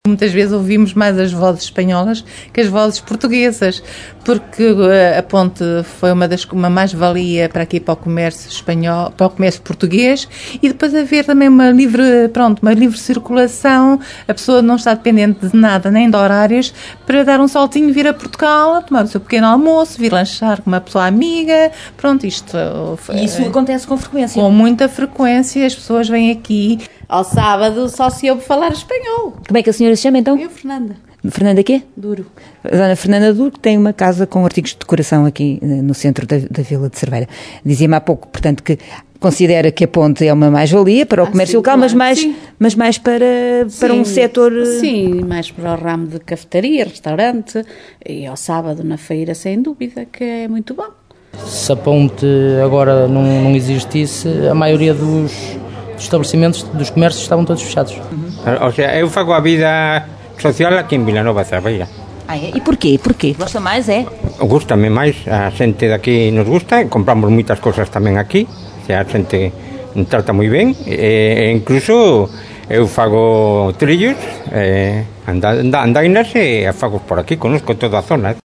Só em 2004 foi possível dar corpo a uma ambição antiga dos dois municípios. O autarca português, Fernando Nogueira, revela, uma década depois, os passos que percorreu o projecto da ponte da Amizade, até ser concretizado: